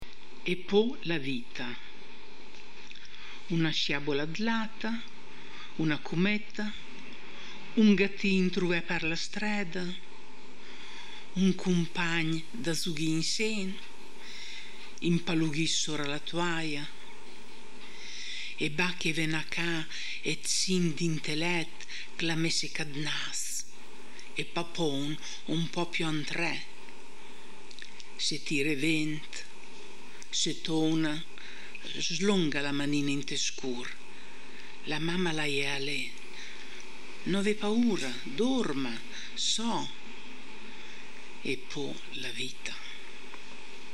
voce recitante